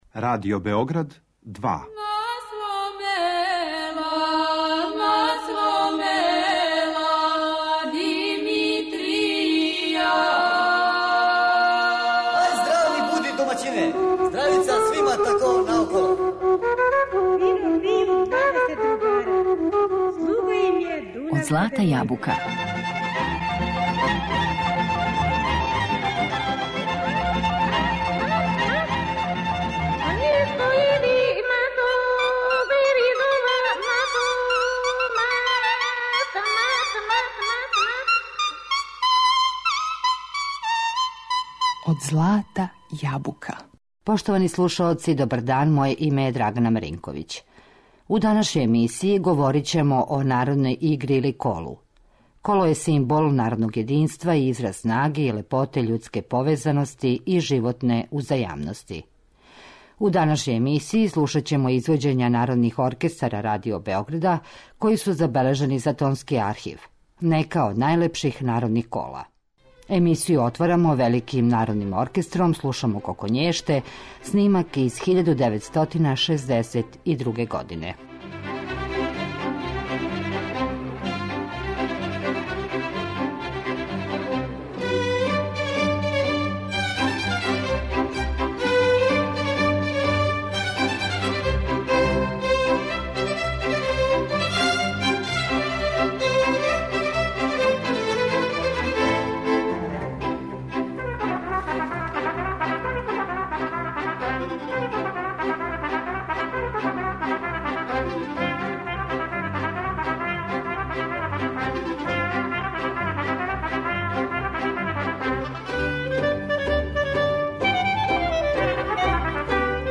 Слушаћемо одабрана и изузетно популарна народна кола која се налазе у нашем звучном архиву, у извођењу народних оркестара Радио Београда.